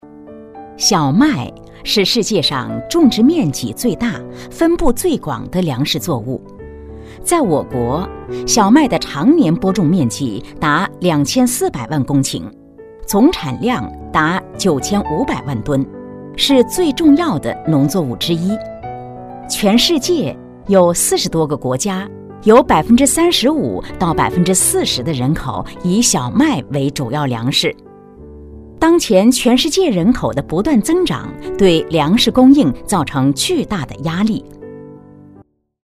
记录片配音：
农作物小麦女56号
成熟稳重 舌尖美食
大气稳重女音，声线偏正式一些。